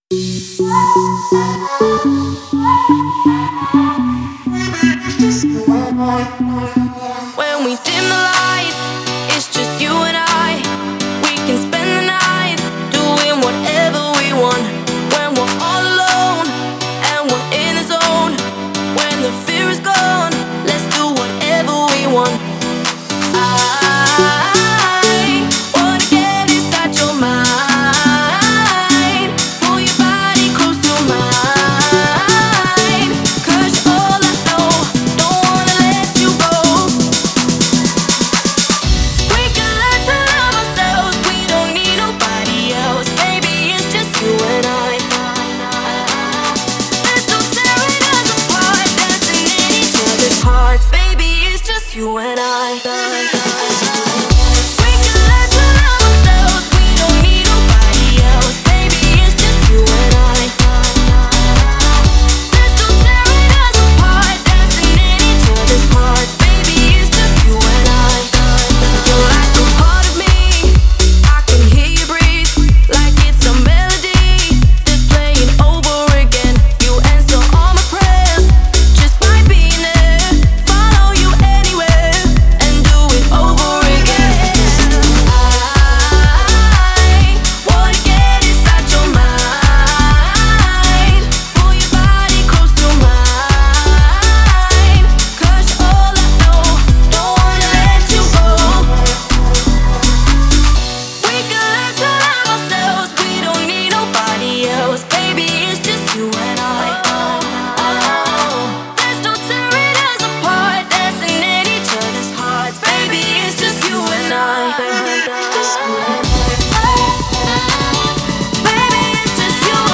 это трек в жанре электронной поп-музыки